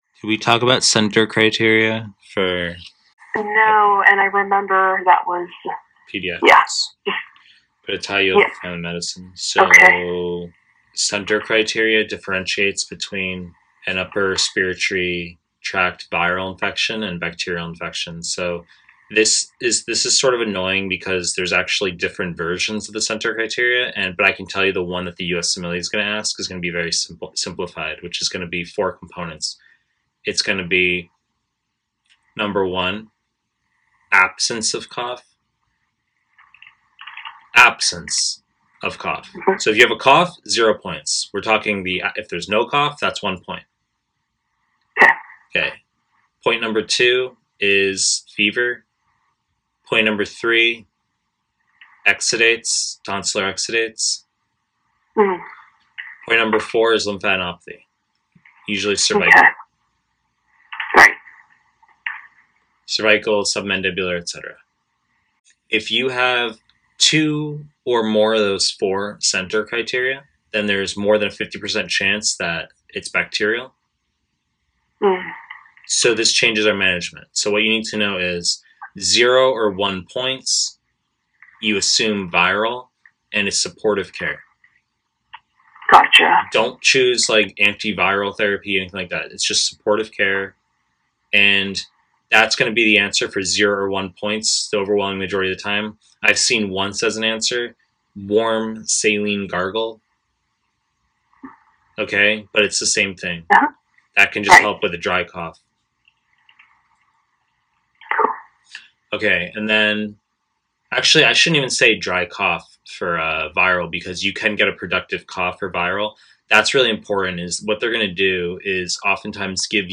Family medicine / Pediatrics / Pre-recorded lectures